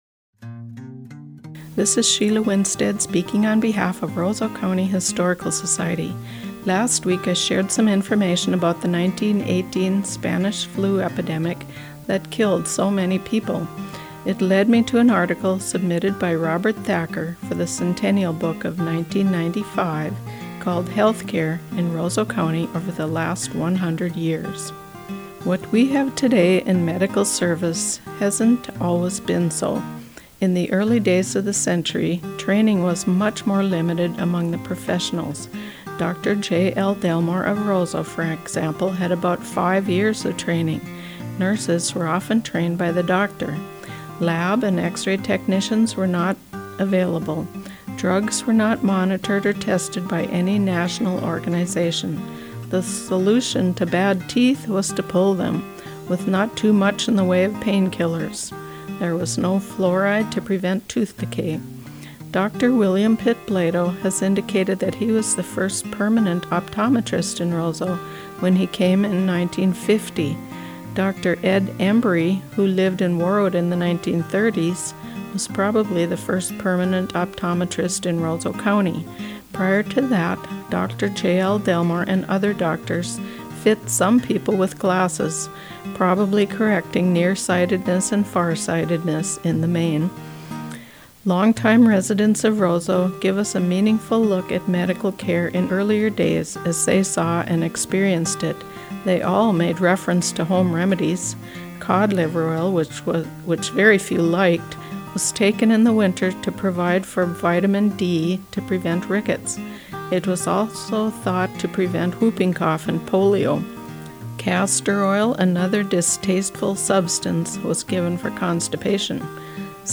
Sunday Morning Radio Readings – July 2018